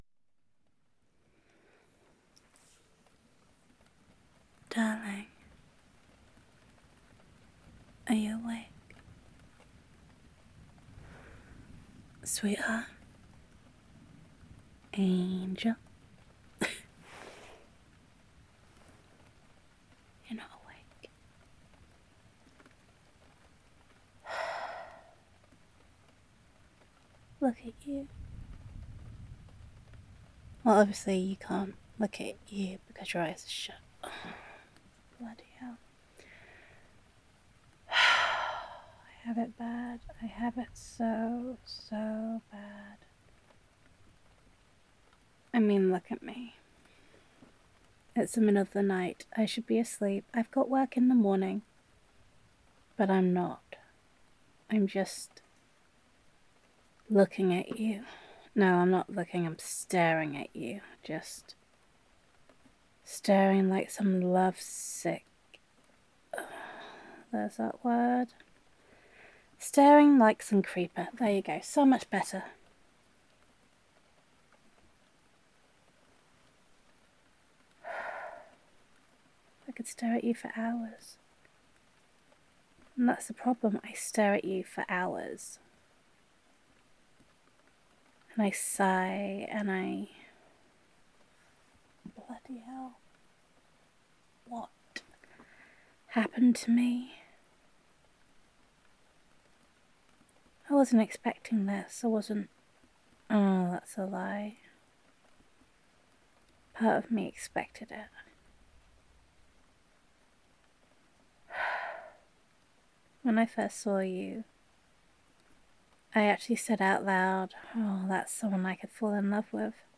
[F4A] Sneaky Love Ninja [Watching You Sleep][Cuddlesome][Rambly][Sighing][Smitten and Grumpy][Stupid in Love][Late Night Adoration][Girlfriend Roleplay][Gender Neutral][Adorkably Confessing My Love to You While You Sleep]